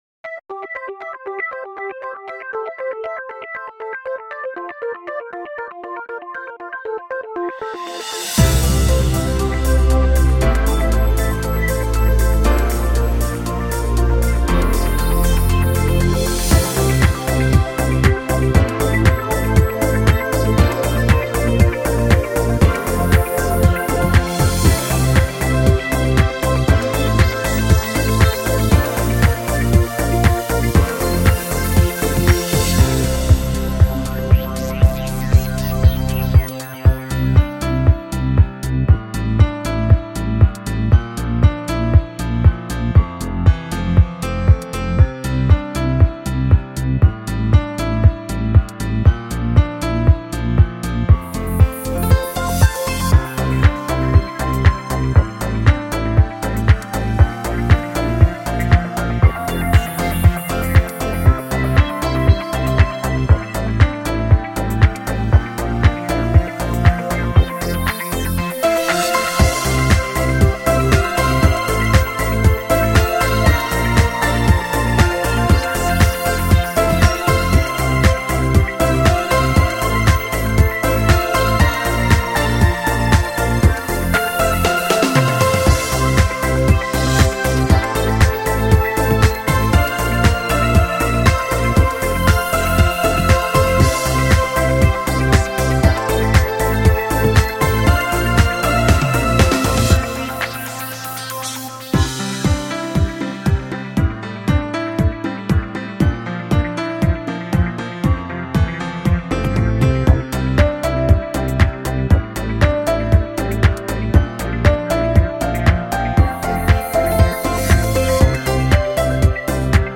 与Electro-chill完美结合，富含Downtempo节奏，构成了12曲旋律优美的New Age
音乐，整辑音乐具有丰富的层次和空间感，令听者感觉非常放松愉悦。